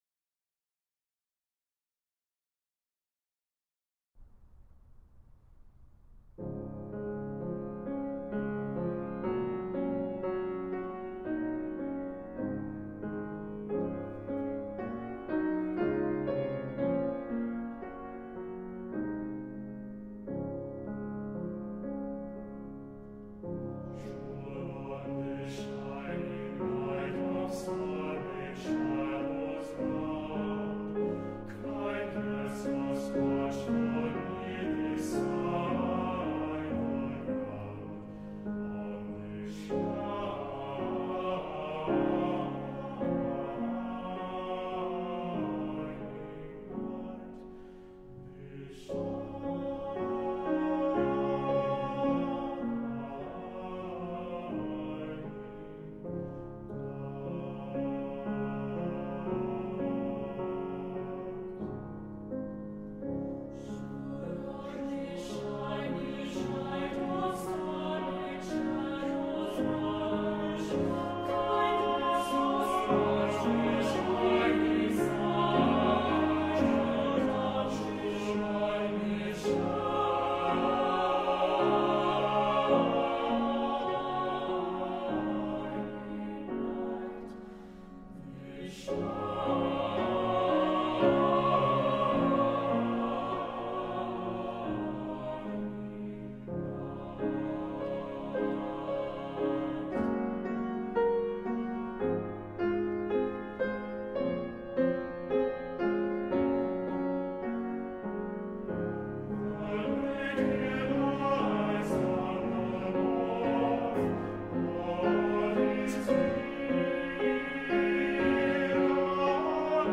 L’Ensemble vocal et le Chœur de chambre de l’École de musique de l’Université de Sherbrooke
Ces deux ensembles vocaux offriront un programme d’œuvres contemporaines de chant choral dont certaines composées tout récemment.